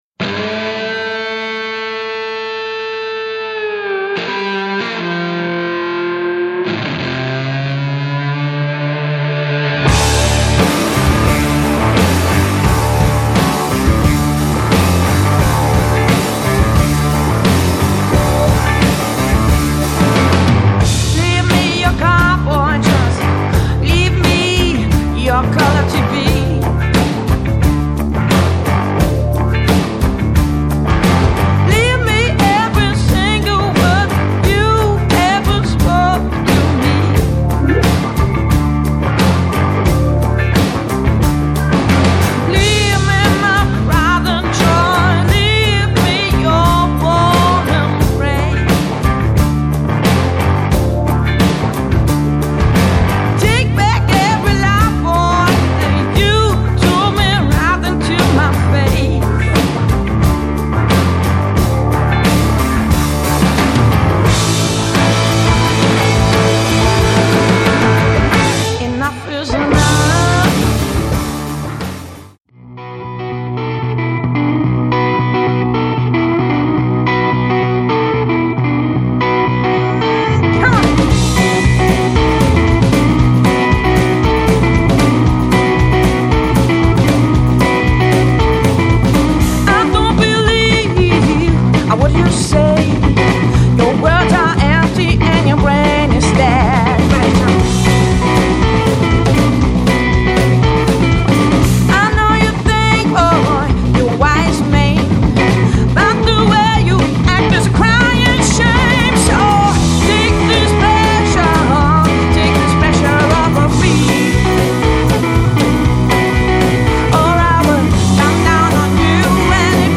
pedal steel guitar
hammond organ, rhodes, piano, accordeon, pump organ
upright bass